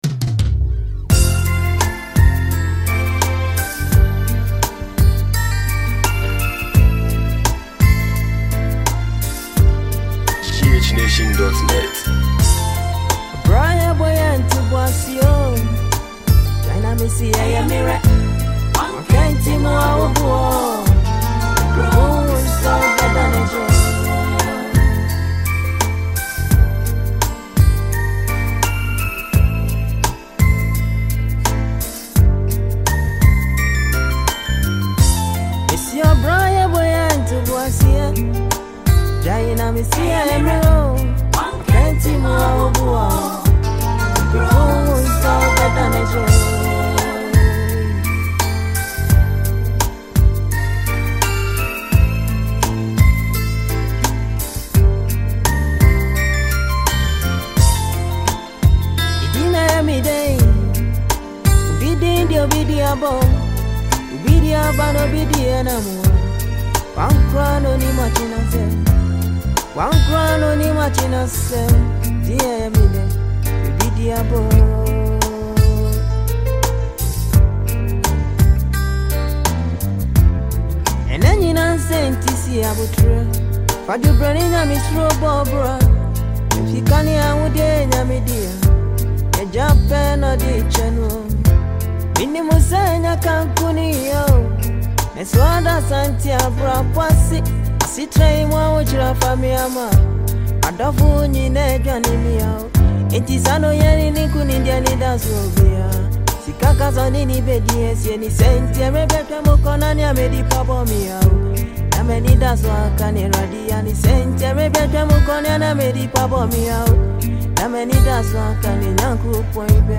old classical song